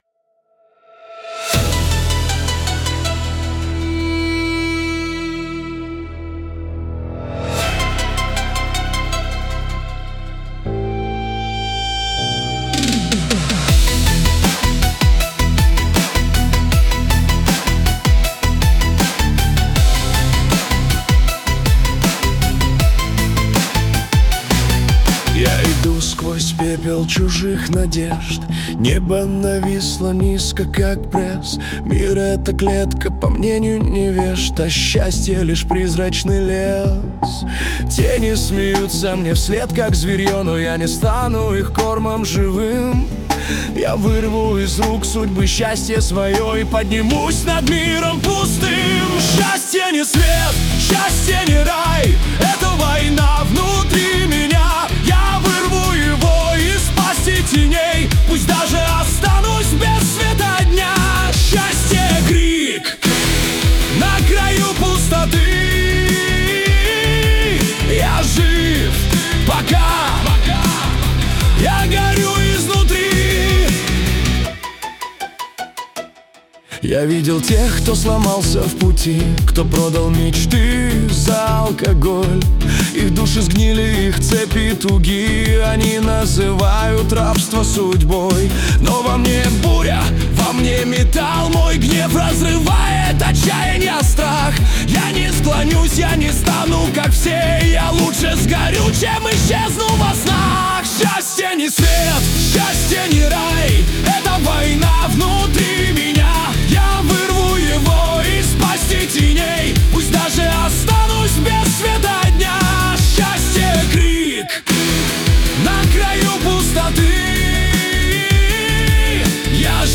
хэви-металл, средний темп, электрогитара, живые ударные, энергичное звучание
Что можно улучшить: роль гитары размыта — она просто «присутствует» в аранжировке.